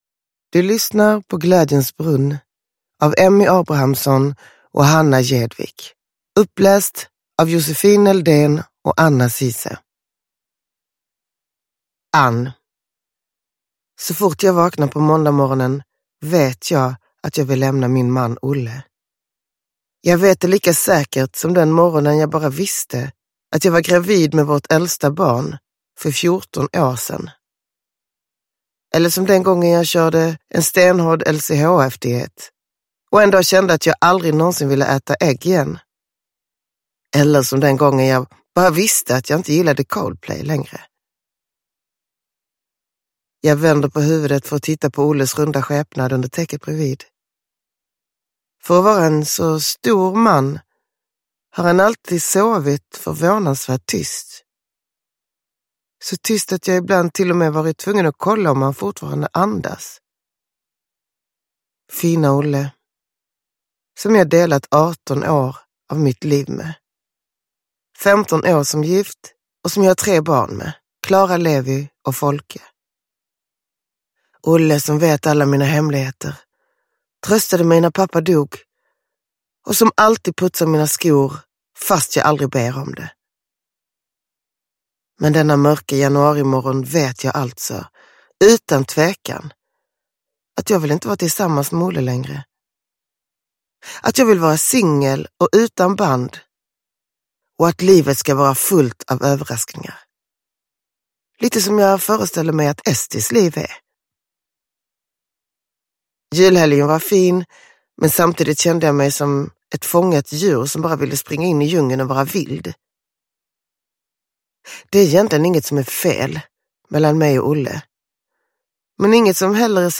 Glädjens brunn – Ljudbok